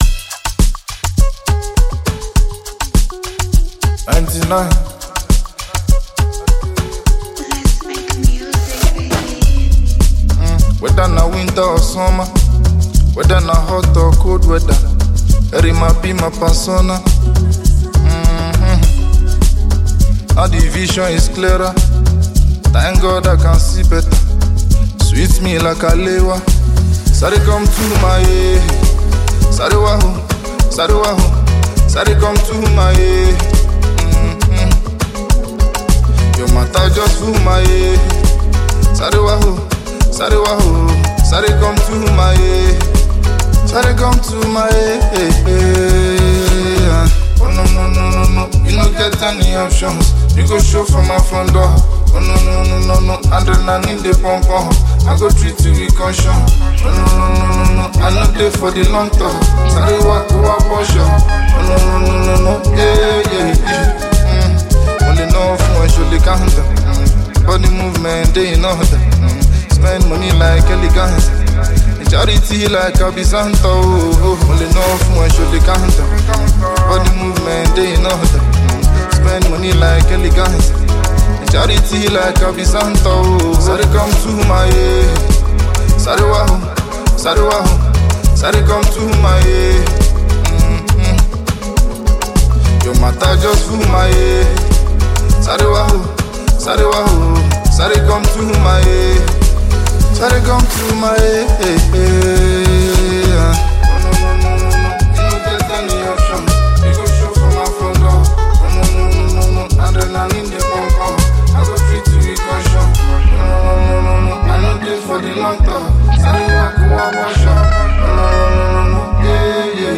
Afropop
motivation and dance-ready energy.